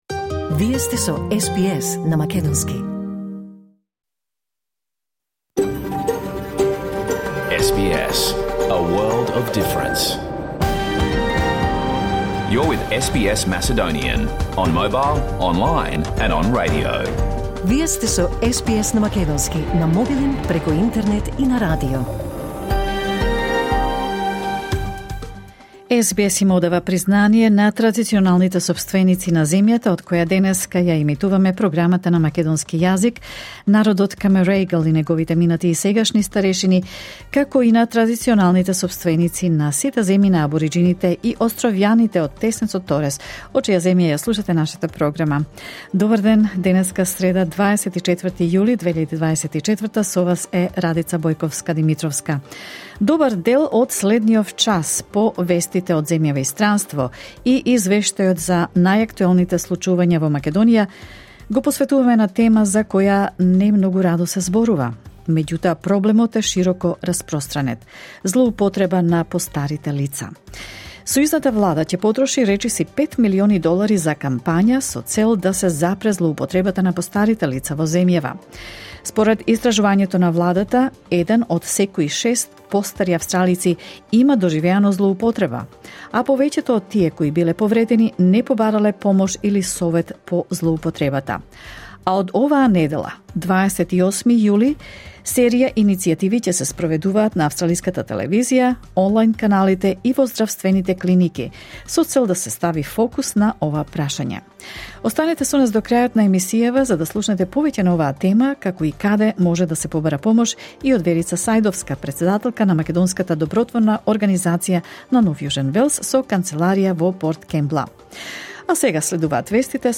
SBS Macedonian Program Live on Air 24 July 2024